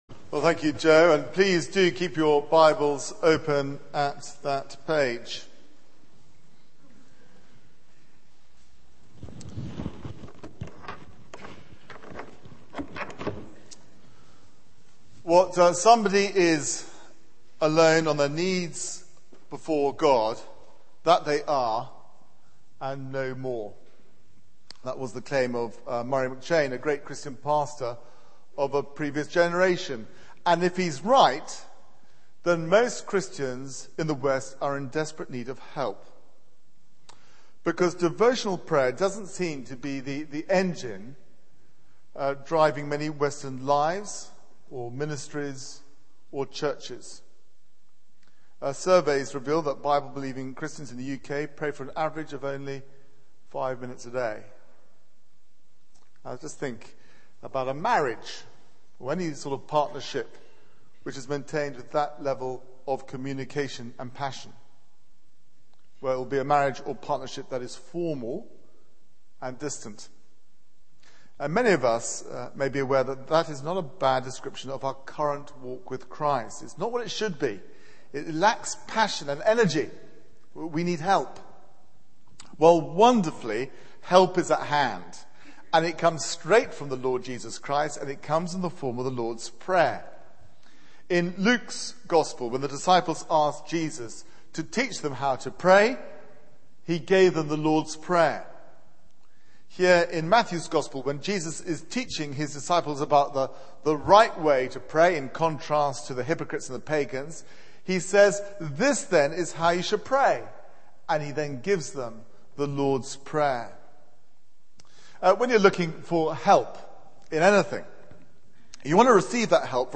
Media for 9:15am Service
Theme: 'Our Father in Heaven' Sermon